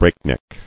[break·neck]